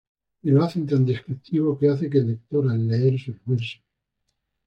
des‧crip‧ti‧vo
/deskɾibˈtibo/